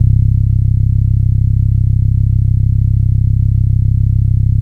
Index of /90_sSampleCDs/Best Service ProSamples vol.48 - Disco Fever [AKAI] 1CD/Partition D/SYNTH-BASSES